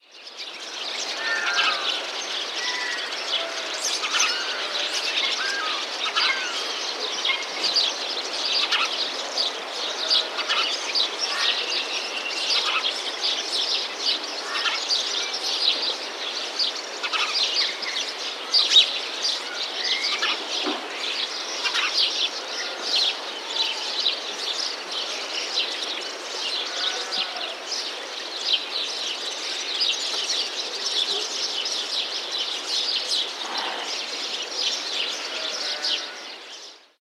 Ambiente de pájaros 1
Sonidos: Animales Sonidos: Rural